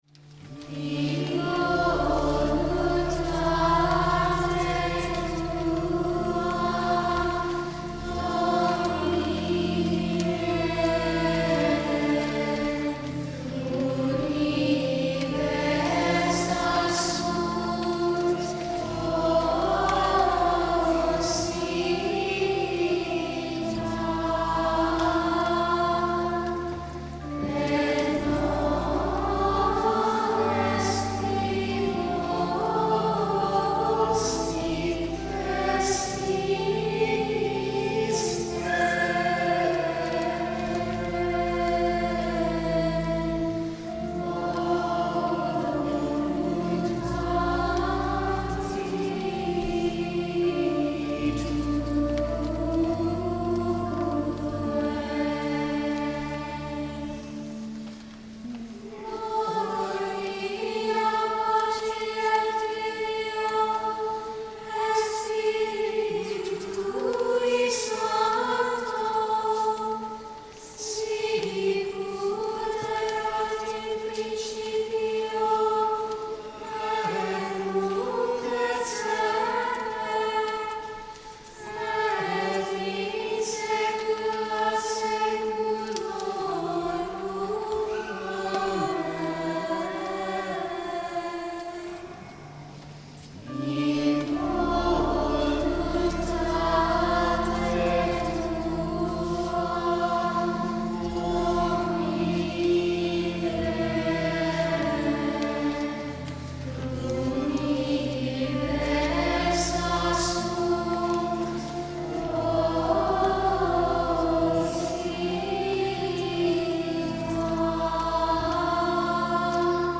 HIS YEAR, our 40-voice children’s choir has taken on a bold new goal: to proclaim the Gregorian Propers within the Novus Ordo Mass.
Mp3 Download • Live Rec.
—“In voluntáte tua, Dómine” (Abr.) • Ave Maria Parish Children’s Choir
organist
In-voluntate-tua-Domine-Childrens-Choir.mp3